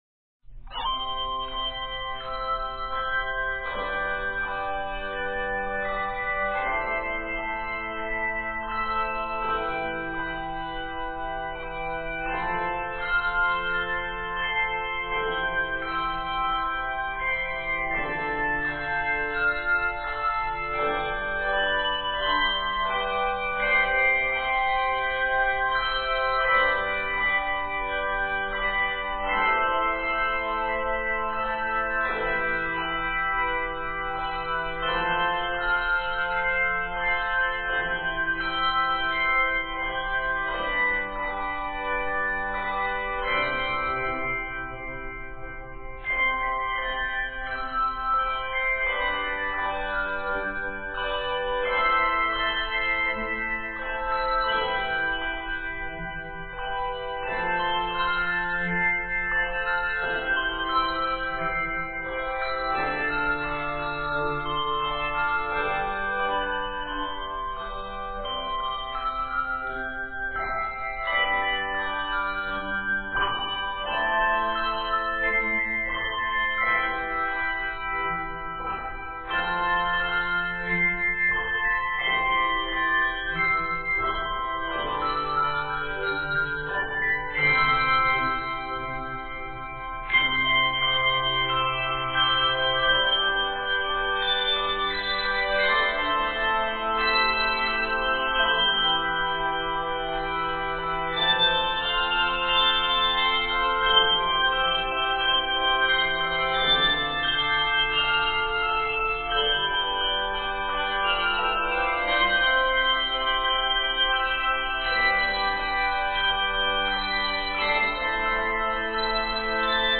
Written in C Major, measures total 64.